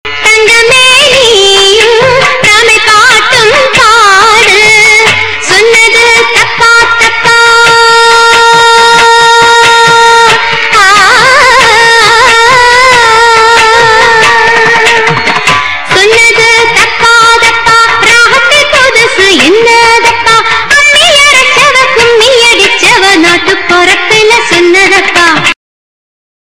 என்பது ஒரு தமிழ்த் திரைப்படப் பாடலில் இடம்பெறும் ஓர்
சிந்து) இதனைப் பாடிய பாடகி இவ்வடியை இருமுறை பாடுவார்.